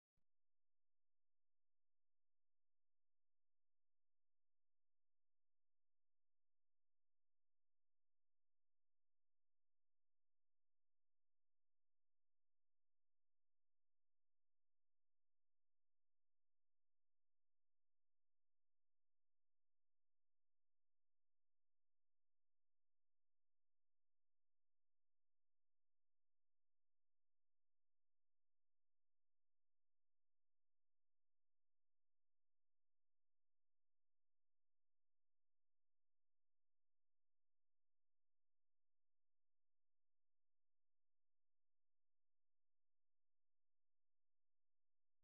woodpecker living his best life 🥰